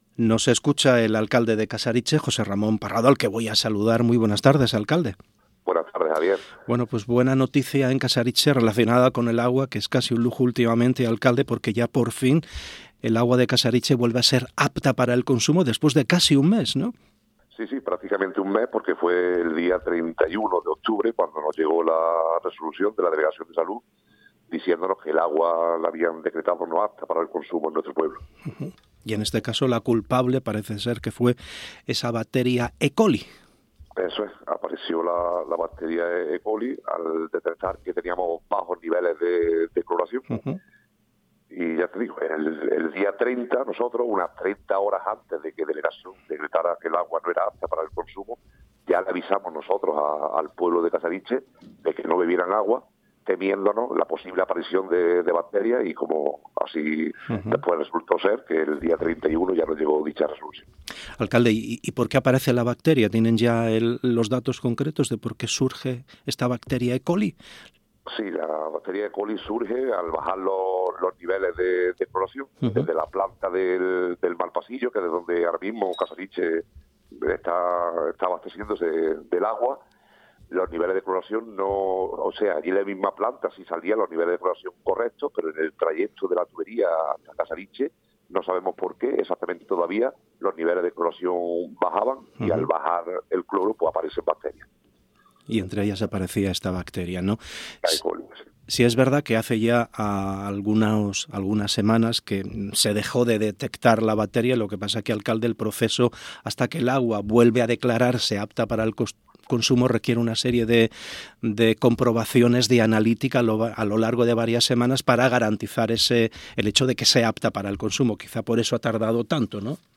Entrevista Jose Ramon Parrado, alcalde de Casariche